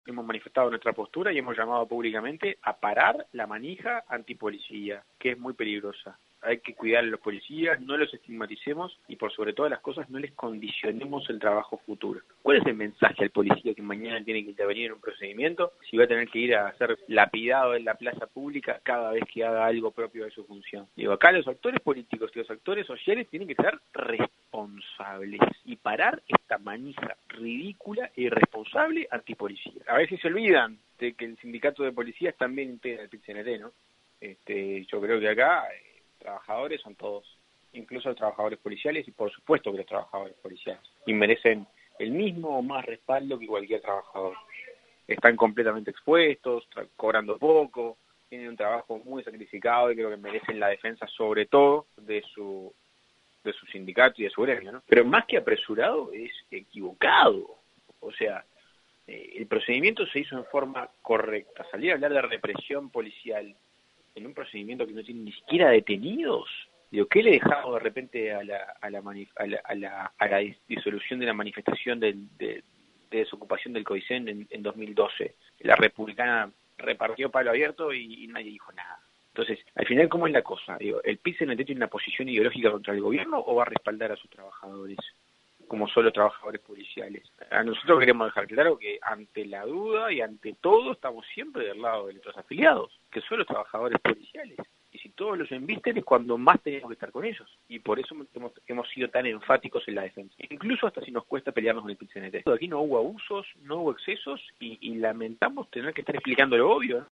En diálogo con 970 Noticias, pidió «cuidar a los policías», no estigmatizarlos y no condicionar «el trabajo futuro».